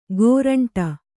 ♪ gōraṇṭa